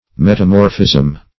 Metamorphism \Met`a*mor"phism\, n. (Geol.)